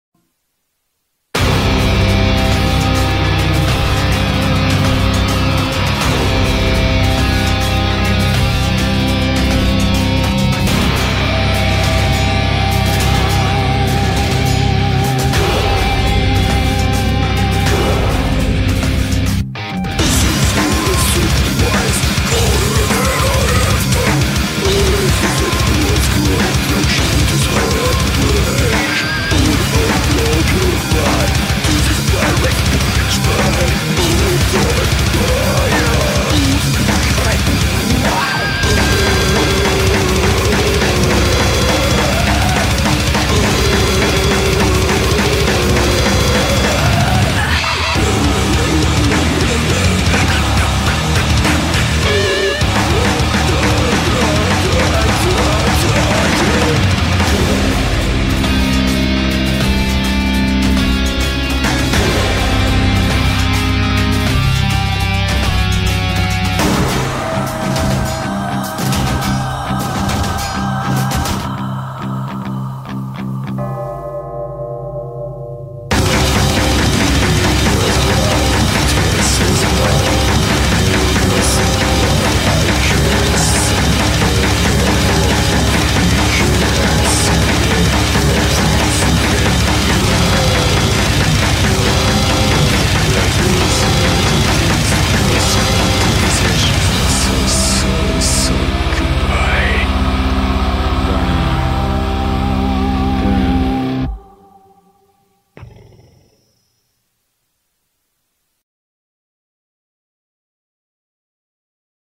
BPM52-412
Genre - Death Metal